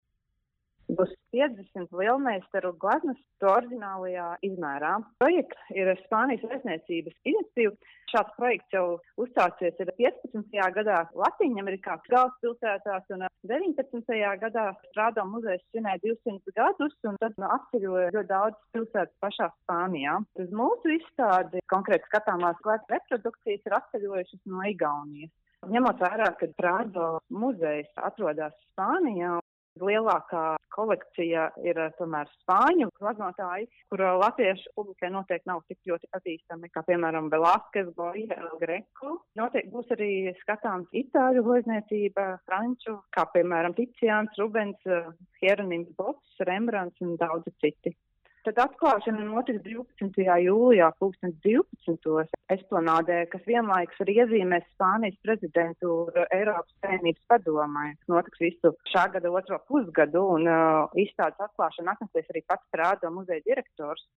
RADIO SKONTO Ziņās par gaidāmo Prado muzeja gleznu lielformāta reprodukciju izstādi Esplanādes parkā